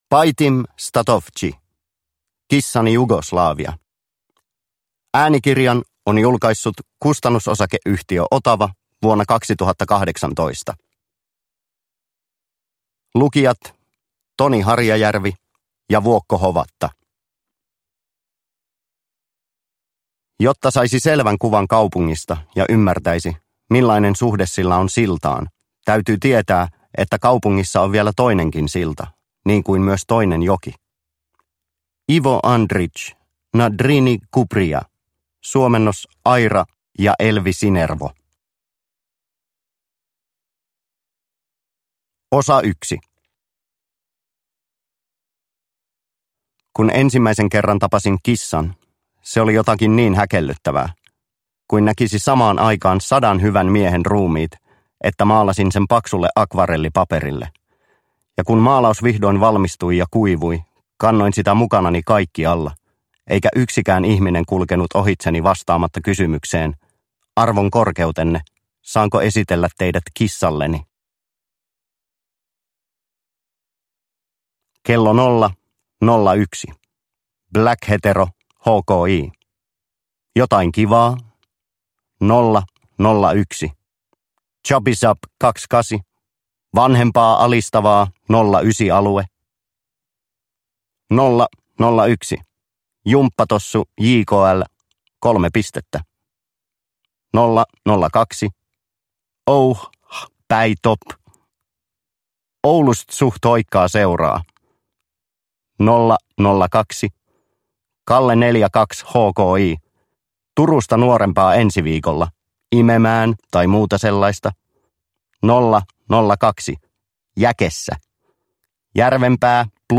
Kissani Jugoslavia – Ljudbok – Laddas ner